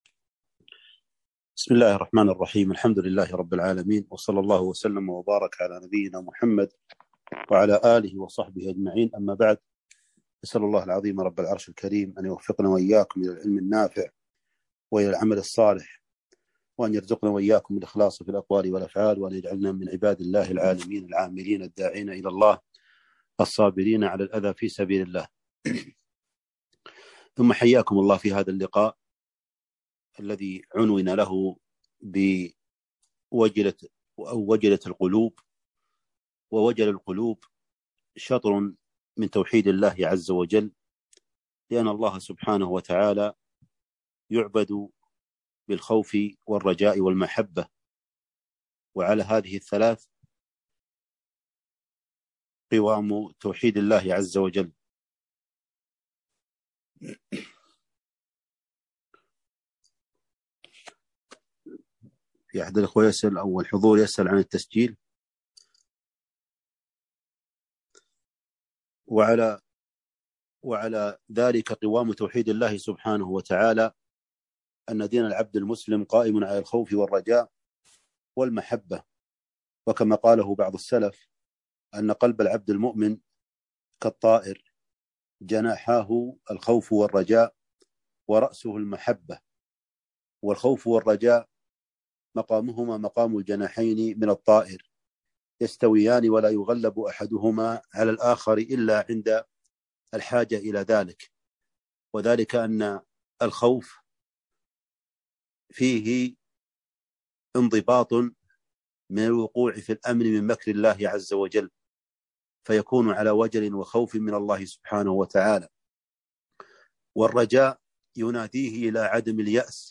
محاضرة - وجلت منها القلوب